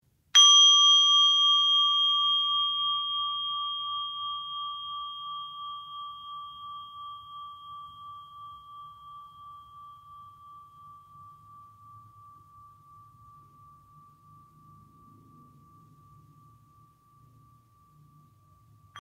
简洁的正念钟声，可用于禅修起止、呼吸练习与短时静心。
正念钟声
mindfulness-bell-sound.mp3